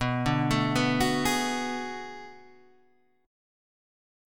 B Minor 13th